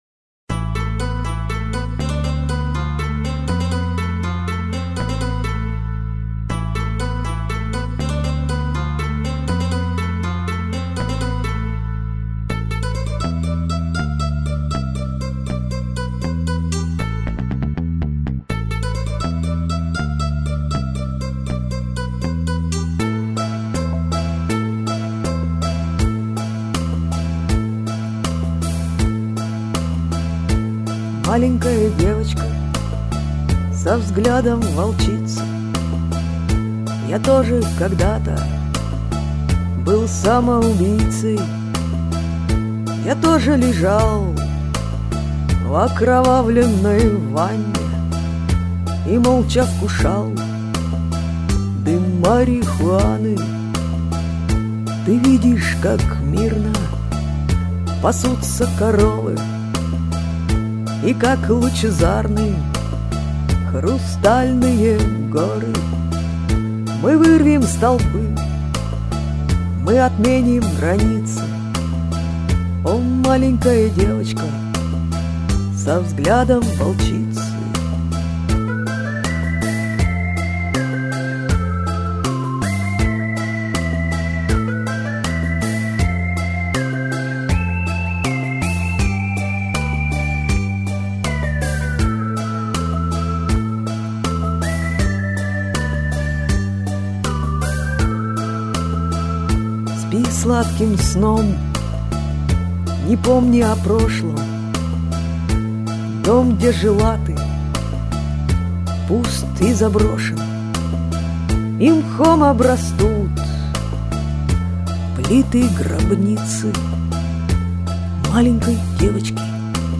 Ну, я-то пою эту песню не по-девичьи ))).....